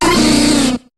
Cri d'Insolourdo dans Pokémon HOME.